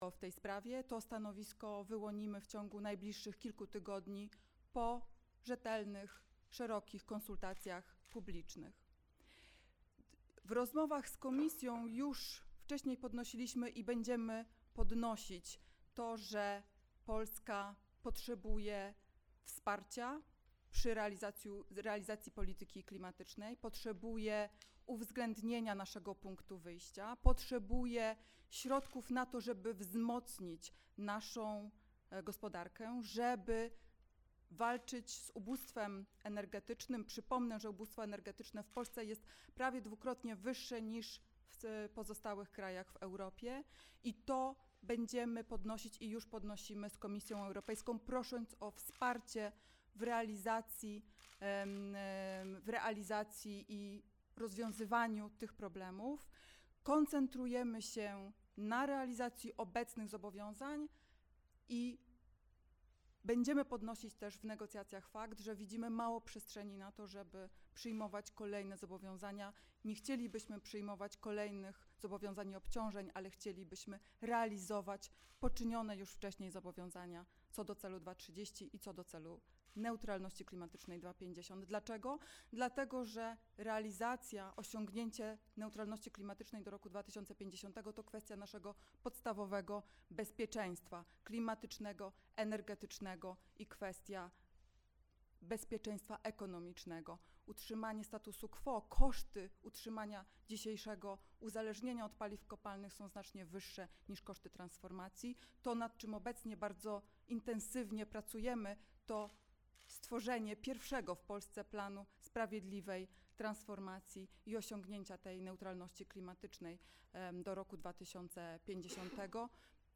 6 lutego 2024 r. w Ministerstwie Klimatu i Środowiska odbyła się konferencja prasowa z udziałem Wiceministry Klimatu i Środowiska Urszuli Zielińskiej oraz Wiceministra Klimatu i Środowiska Krzysztofa Bolesty na temat komunikatu Komisji Europejskiej.
Nagranie audio: konferencja prasowa z udziałem wiceministry klimatu i środowiska Urszuli Zielińskiej oraz wiceministra klimatu i środowiska Krzysztofa Bolesty na temat komunikatu Komisji Europejskiej.